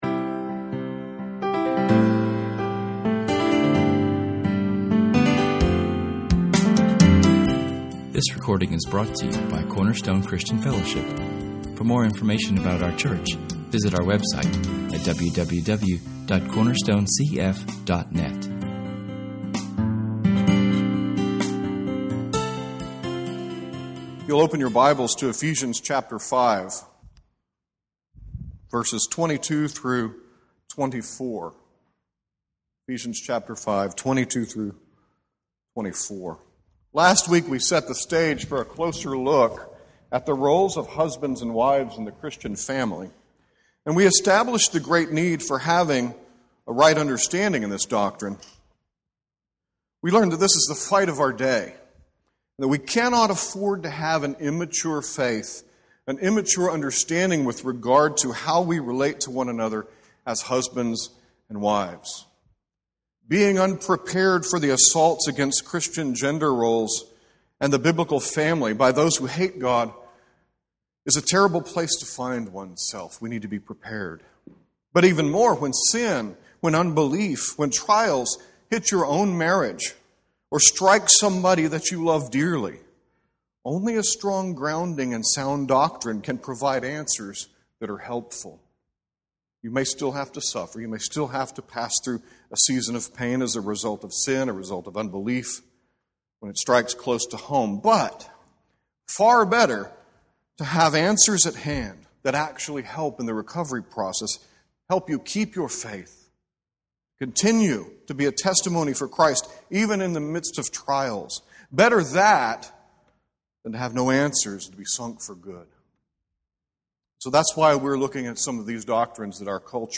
In this sermon, we discover that true feminine value is not found in the autonomous rejection of God’s design for marriage, but in the gospel-declaring, God-ordained authority structure of the one-flesh union of husband and wife.